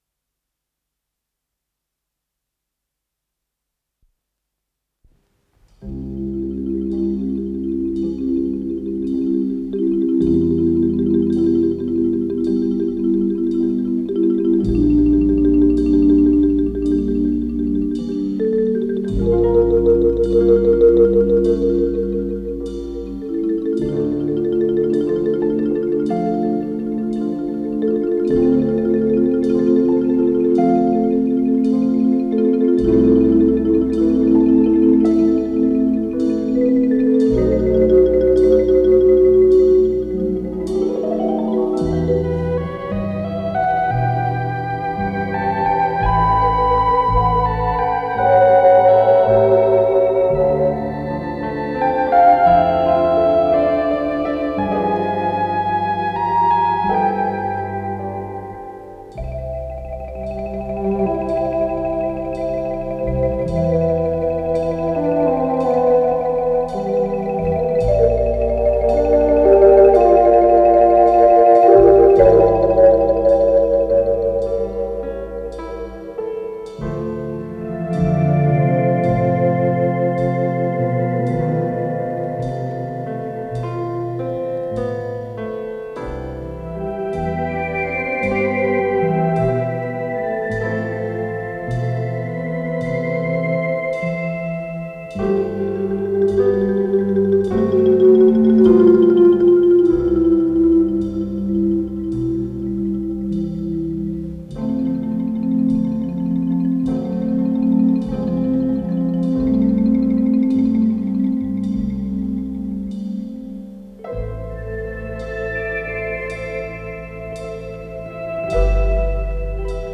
с профессиональной магнитной ленты
маримбафон
ВариантДубль моно